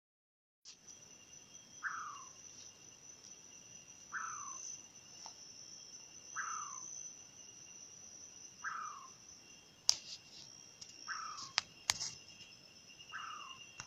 Atajacaminos Ocelado (Nyctiphrynus ocellatus)
Localidad o área protegida: Parque Provincial Cruce Caballero
Condición: Silvestre
Certeza: Fotografiada, Vocalización Grabada
Atajacaminos-Ocelado.mp3